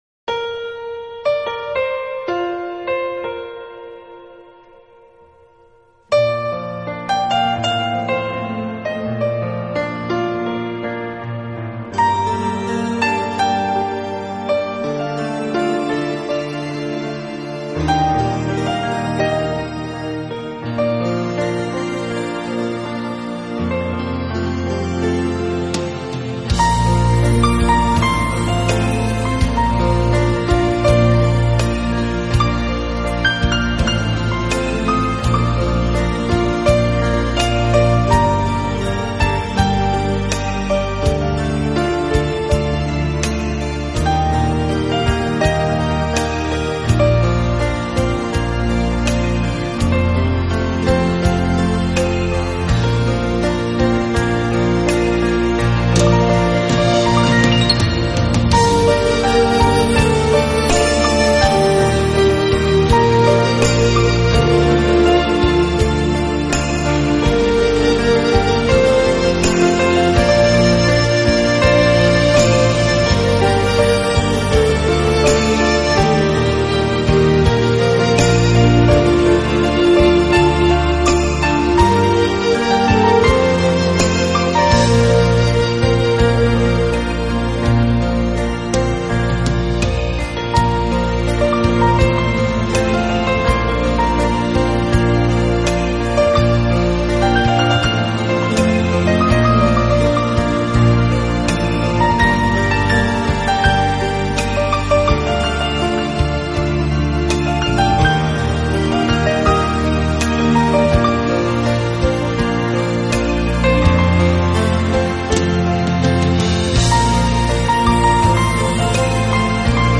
新世纪钢琴
音乐风格: Newage